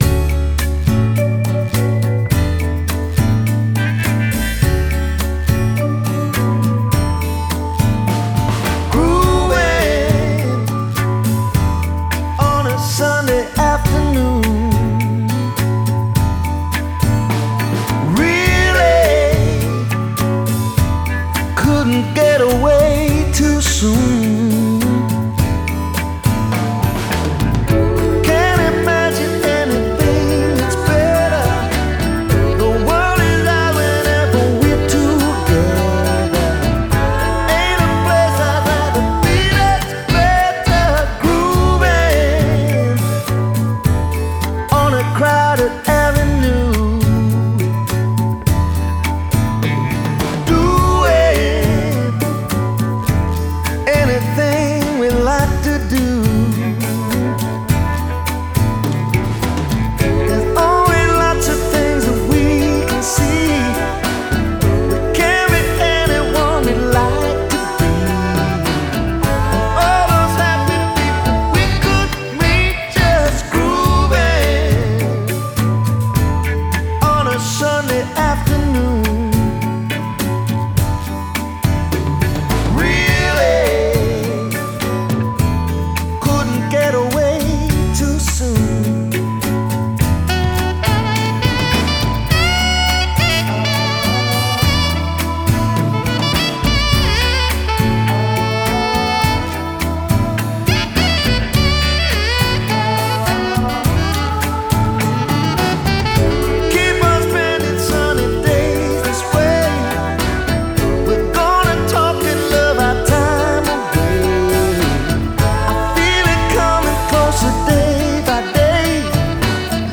Genre: Pop/Rock, Blue-eyed Soul